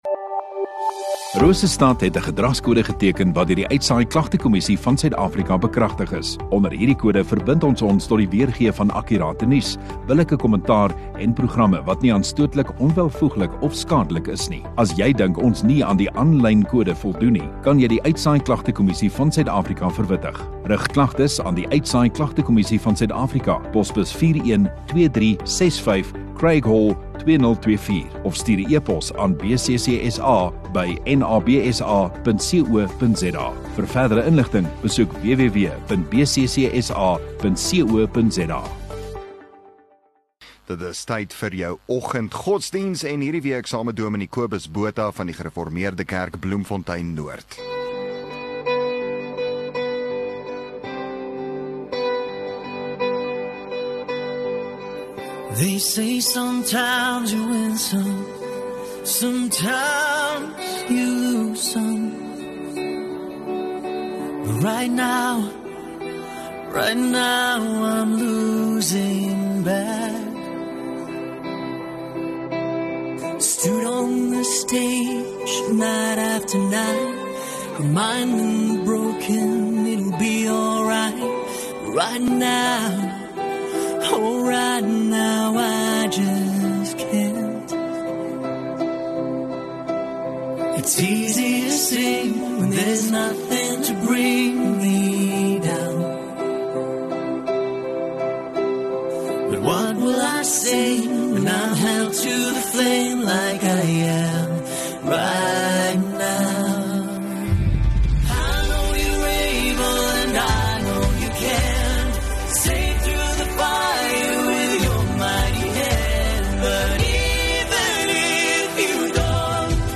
25 Jun Woensdag Oggenddiens